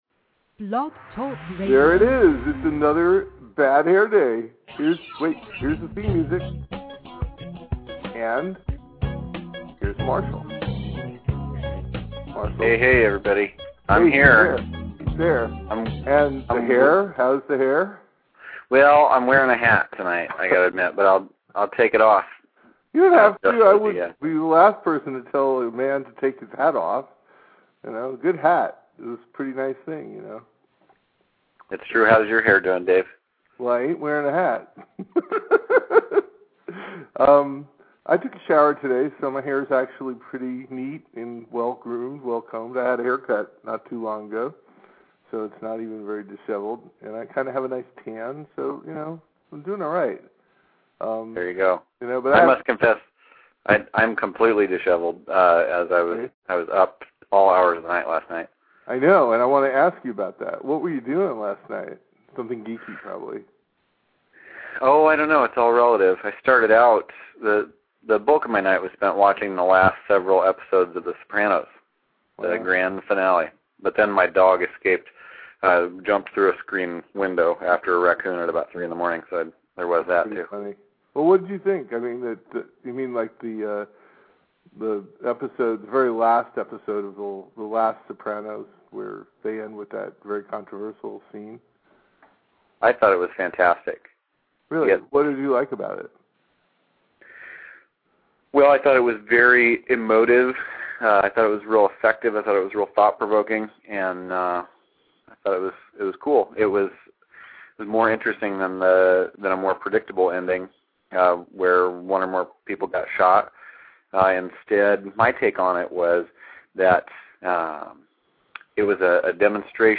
We kept talking after the show ended, and thought it wasn't being recorded, but you know what -- it was!